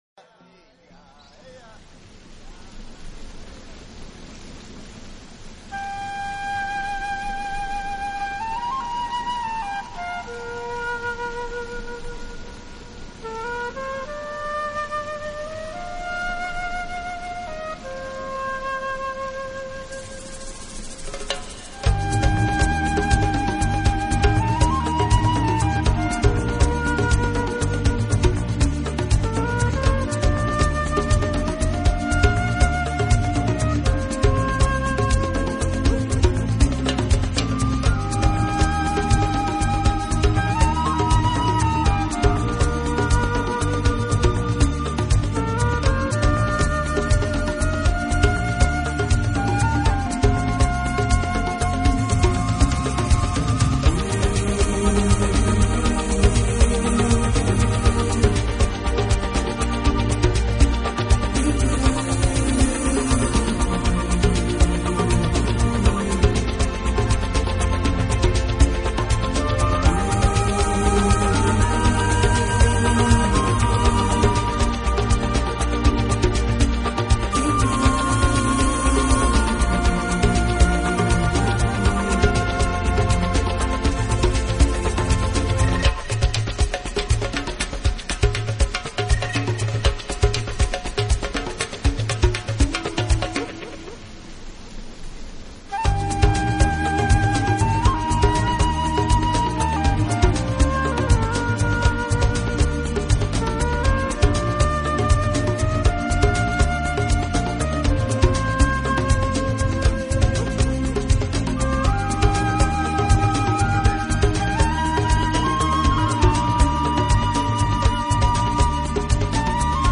【新世纪长笛】
本专辑内共收录了11首音乐，全为意境化的乐曲，旋律细腻，极具大自然
气息，专辑内多首乐曲更刻意混入了流水声、雀鸟声以及昆虫的叫声，效果自然像真不落
固然是本专辑的主角，它顺滑流畅，轻柔潇酒，很能配合大自然的主题。
口笛、吉他及合成电子皆同样精彩，叫人喜出望外。